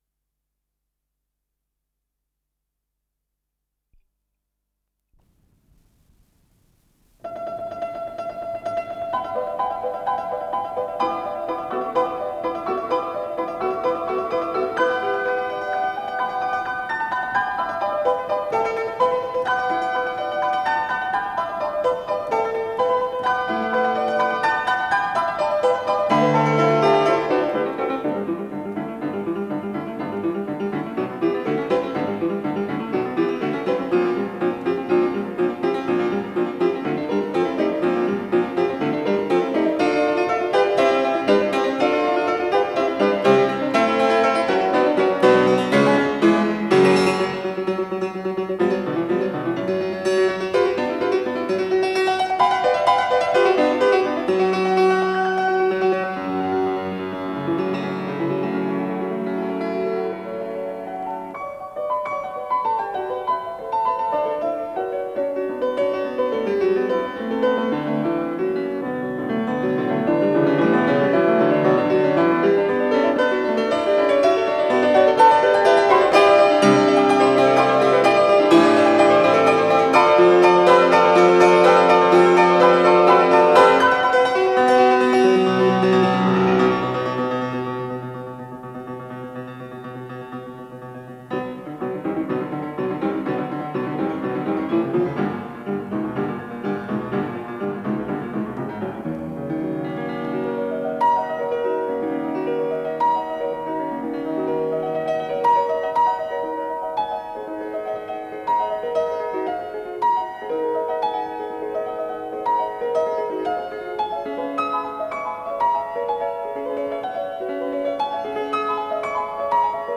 с профессиональной магнитной ленты
ПодзаголовокСюита для фортепиано
ИсполнителиАнатолий Ведерников - фортепиано